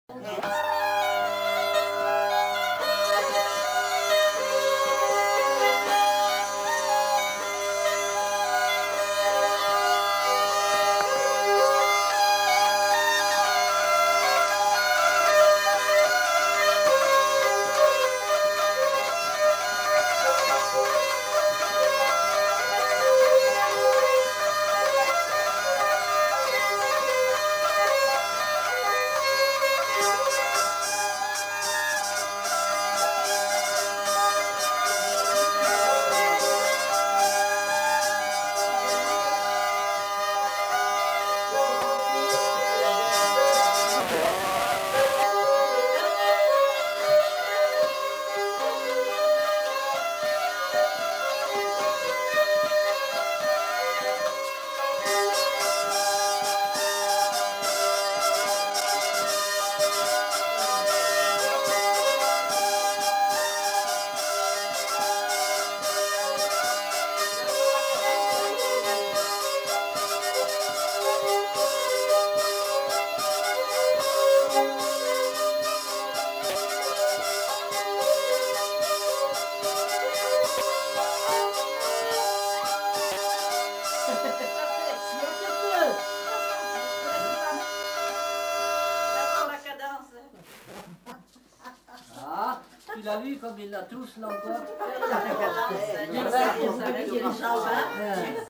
Aire culturelle : Limousin
Lieu : Lacombe (lieu-dit)
Genre : morceau instrumental
Instrument de musique : violon ; vielle à roue
Danse : valse
Notes consultables : La vielle à roue est jouée par un des enquêteurs.